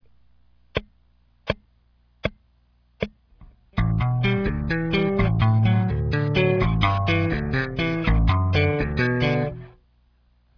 (Ex.1) It consist of three notes or three "attacks" (since some of the notes can be played as muted notes).